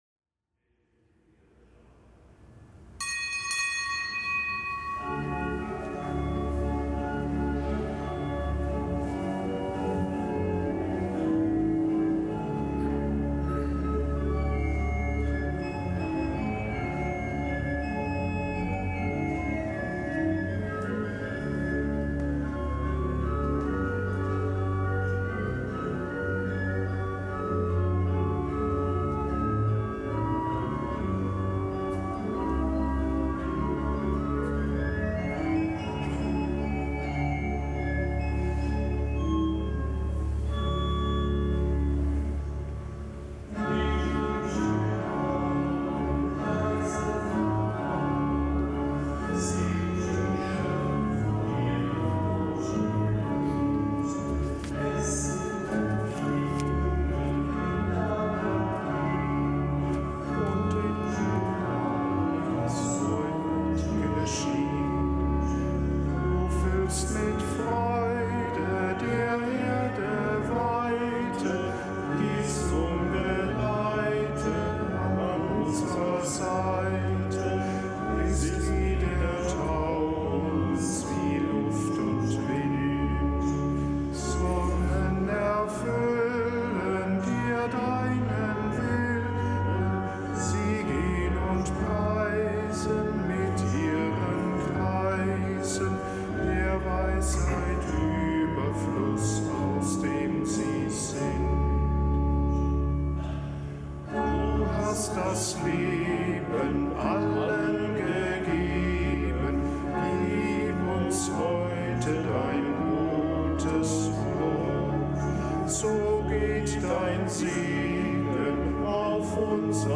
Kapitelsmesse aus dem Kölner Dom am Mittwoch der vierten Woche im Jahreskreis.